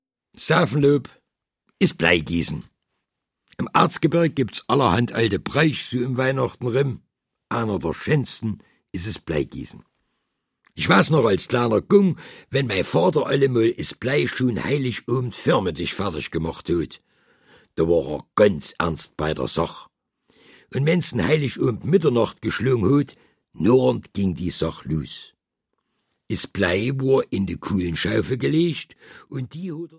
Und weil die meisten mündlich überliefert wurden, sind es Mundart-Geschichten auf „Arzgebirgisch“.
Deutsch - Mundart